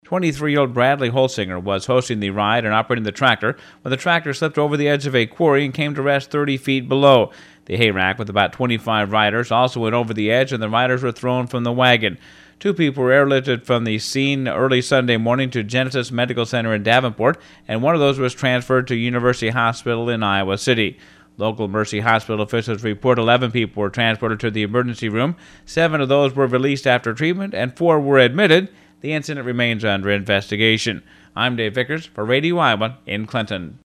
Local Mercy hospital officials report eleven people were transported to the emergency room.